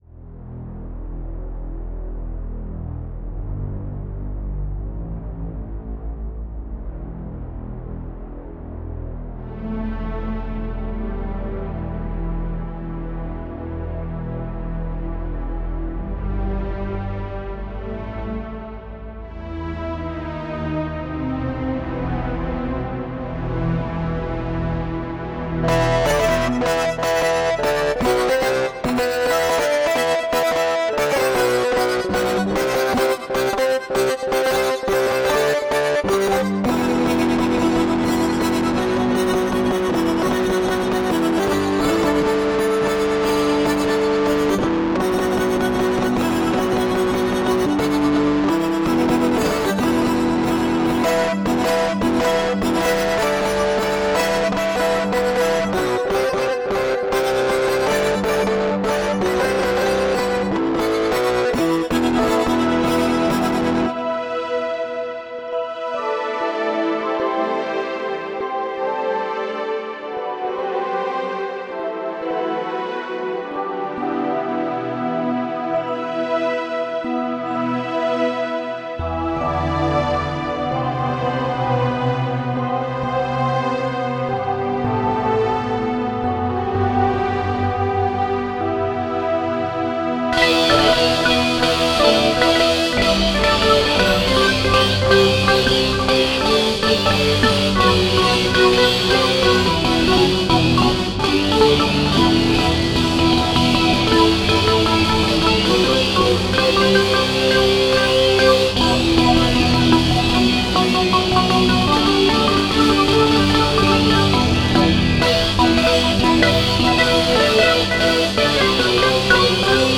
(C) GNU GPL v2 Commentary: Here is an electric guitar and violin song.